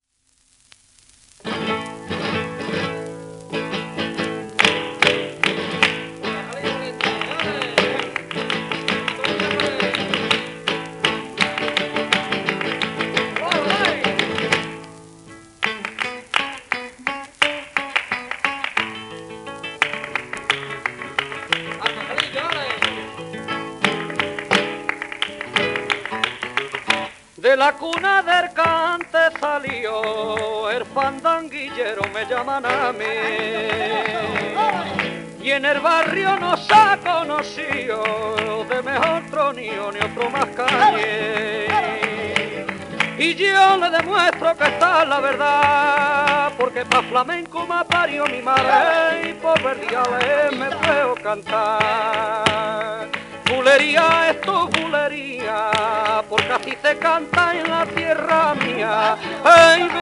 1941年頃の録音
スペイン出身のポピュラー、フラメンコ歌手。